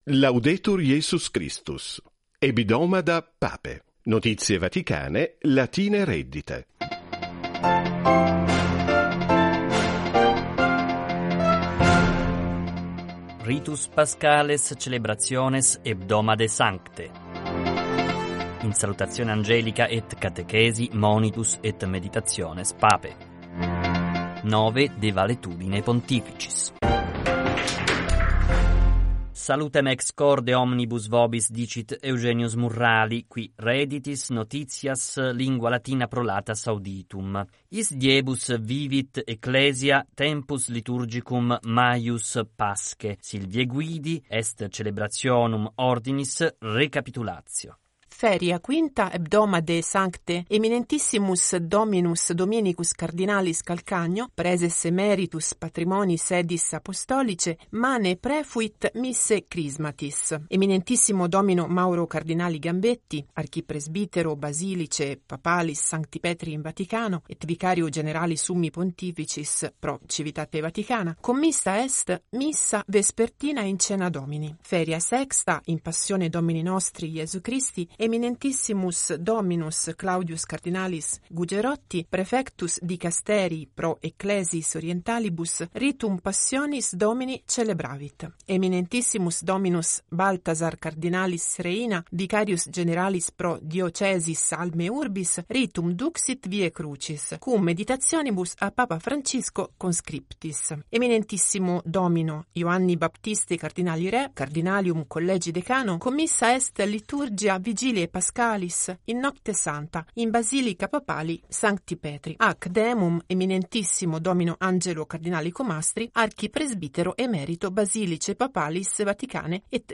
Vatican Radio News in Latin ➕ Tilaa ➕ Tilaa ✔ Tilatut ✔ Tilattu Toista Toistetaan Jaa Merkitse kaikki (ei-)toistetut ...